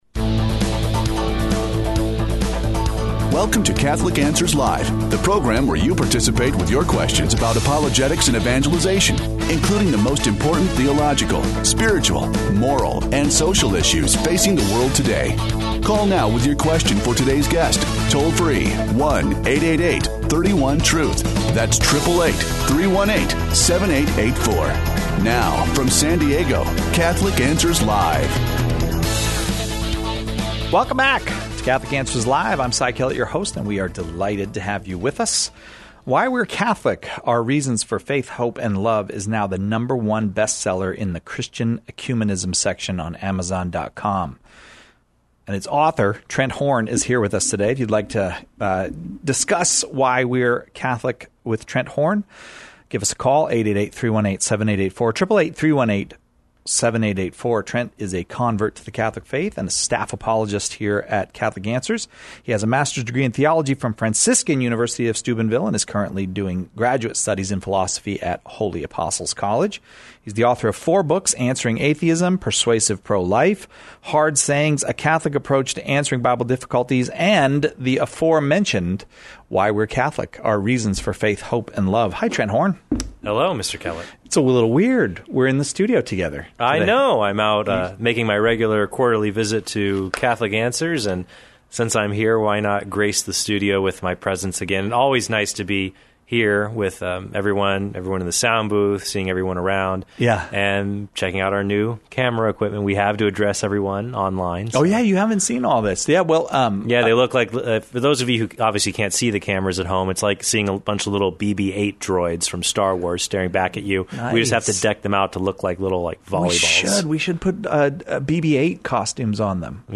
He takes listener questions about various aspects of the Catholic Faith.